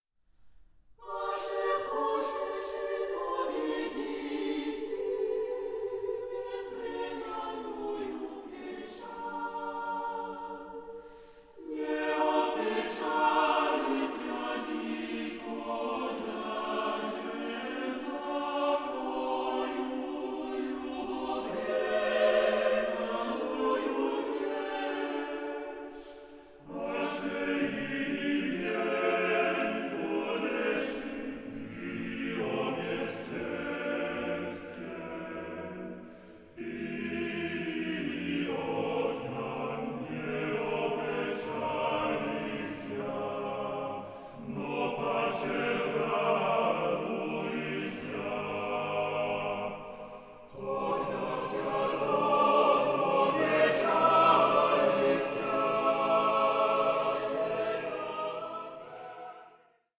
96/24 Stereo  14,99 Select